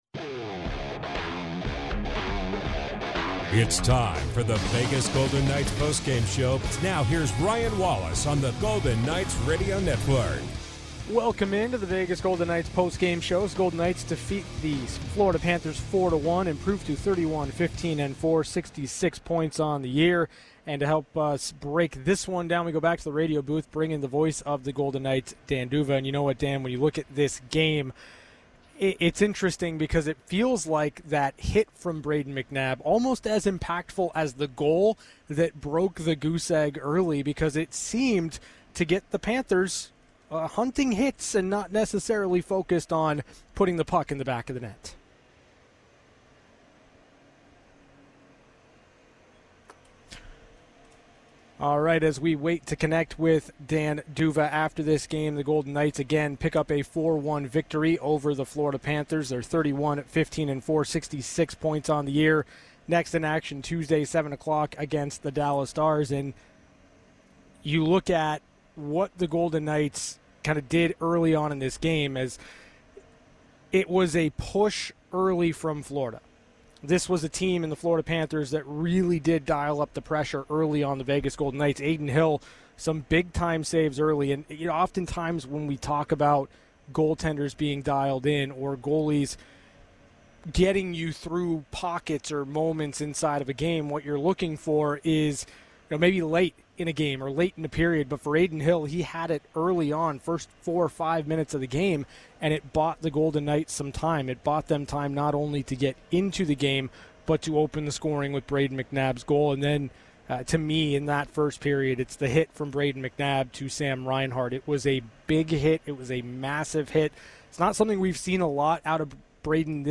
TAKES CALLS AND TEXTS ON THE GOLDEN KNIGHTS 5-2 WIN AT MADISON SQUARE GARDEN VS THE RANGERS.